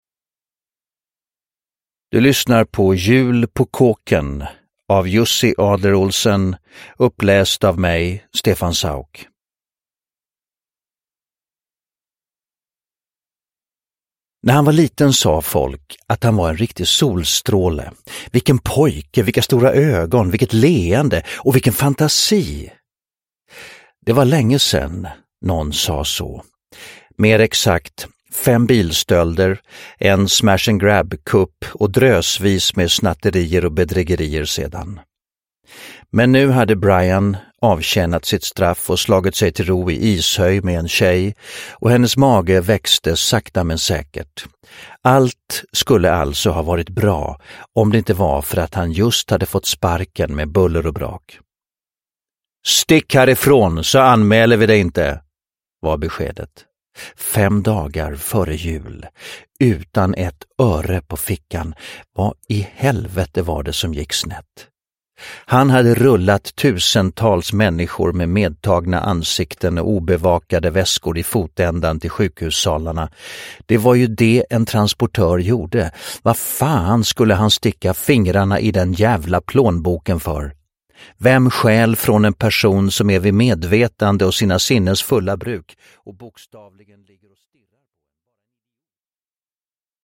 Uppläsare: Stefan Sauk